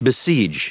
Prononciation du mot besiege en anglais (fichier audio)
Prononciation du mot : besiege